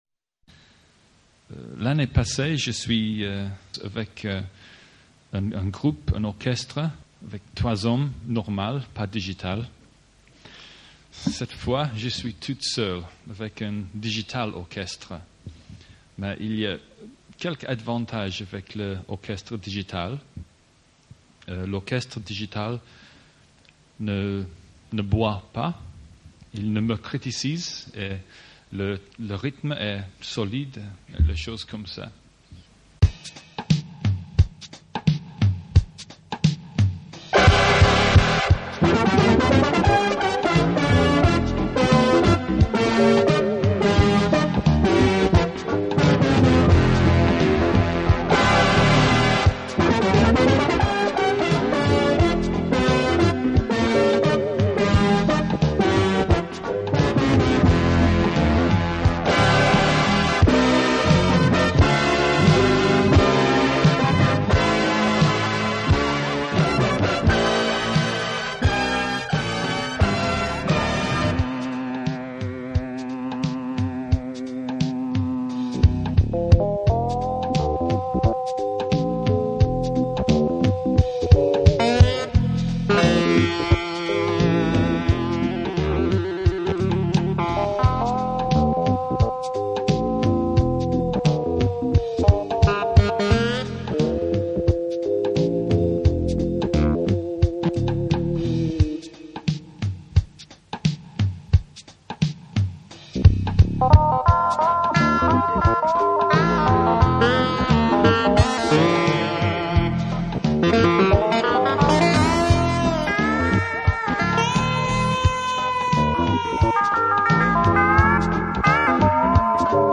Dans ces trois démonstrations, le réalisme est obtenu par la superposition de modules, quelques uns produisant un son d’instrument solo, les autres produisant des nappes.
En jouant avec la pédale et la dynamique de clavier, on obtient un jeu symphonique assez bluffant.
cuivres jazz La FM permet des pêches de cuivres bien grasses !